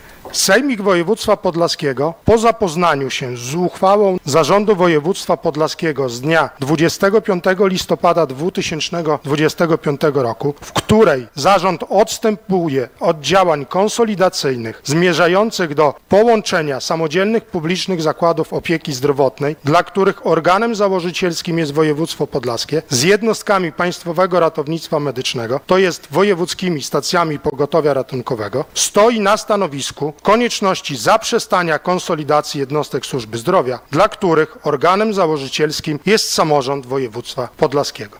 W poniedziałek (1.12), podczas sesji sejmiku województwa podlaskiego, radni jednogłośnie przyjęli wspólne stanowisko. Jego treść przedstawił Artur Kosicki, radny wojewódzki.